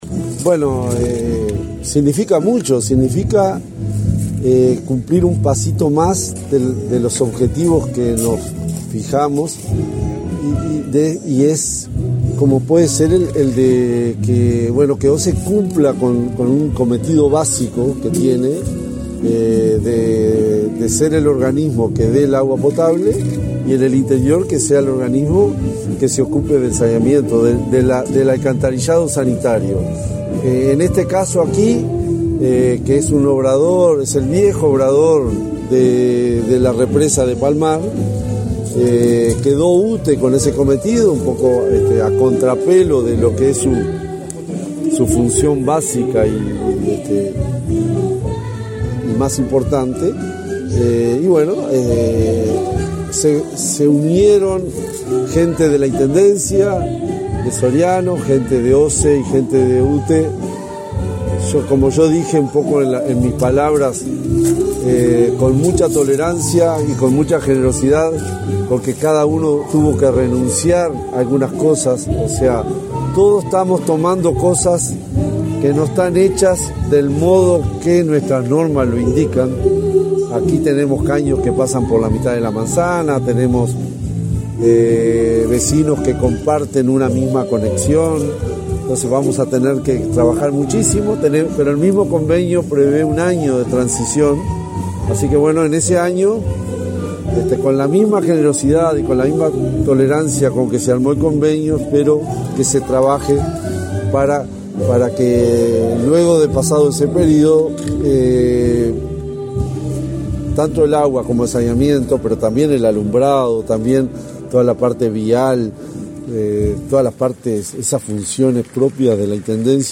Declaraciones a la prensa del presidente de OSE, Raúl Montero